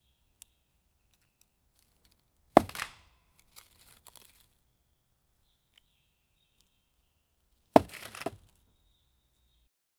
sierra manual cortando un arbol
sierra-manual-cortando-un-4xxe4ocg.wav